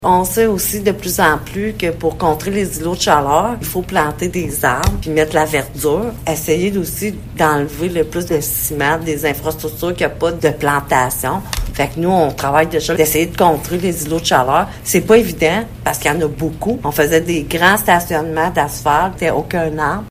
La mairesse de Maniwaki, Francine Fortin, nomme une raison supplémentaire d’inclure la plantation d’arbres dans le plan d’urbanisme de la Ville :